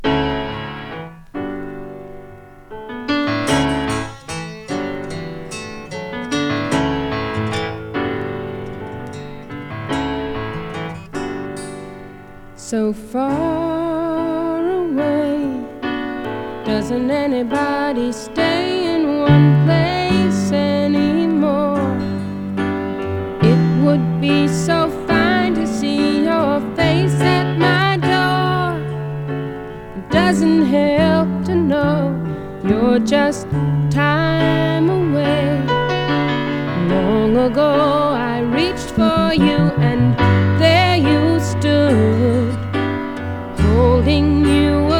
Pop, Rock, SSW　Netherlands　12inchレコード　33rpm　Stereo